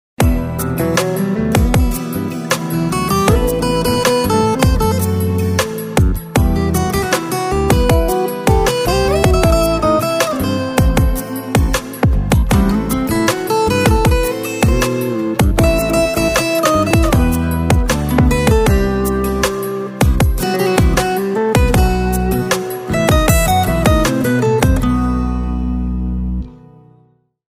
رینگتون احساسی و بیکلام